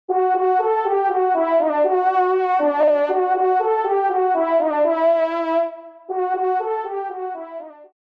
FANFARE
Extrait de l’audio-pédagogique (Tester)
Pupitre de Chant